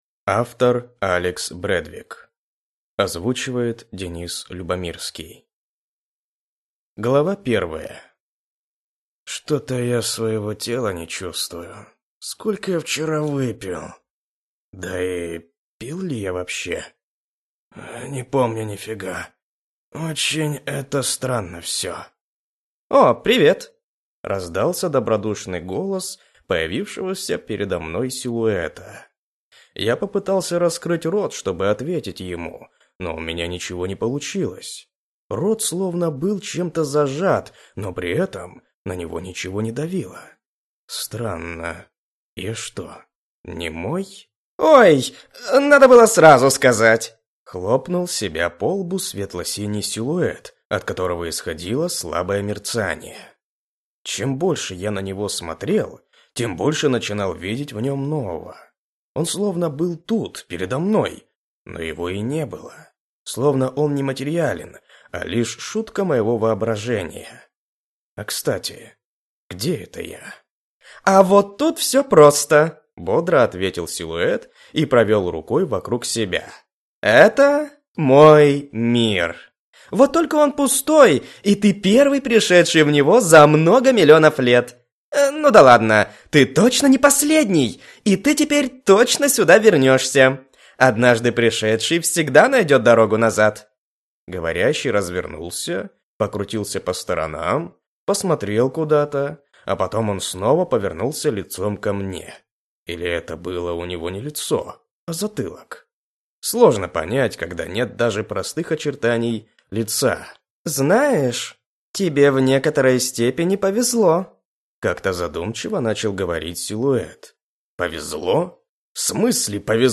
Аудиокнига Иной | Библиотека аудиокниг